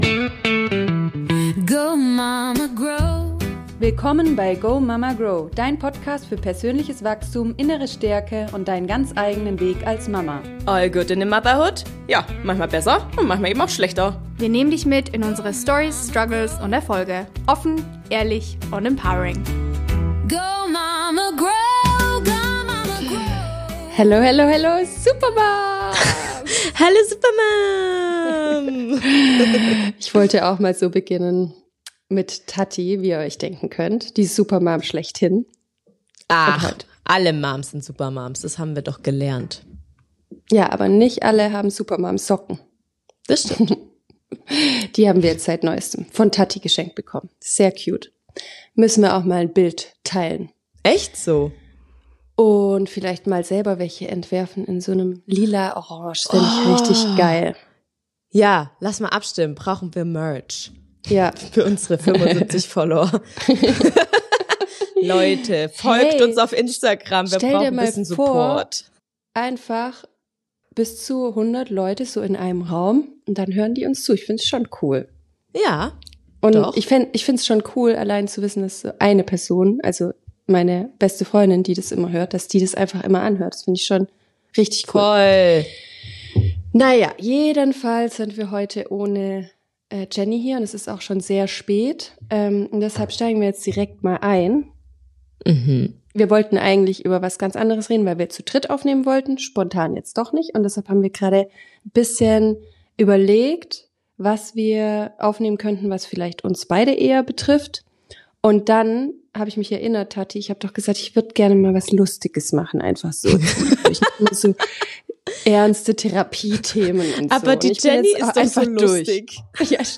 Diese Folge startet spontan und entwickelt sich irgendwo zwischen Deep Talk, Lachflash und unerwarteten Wendungen.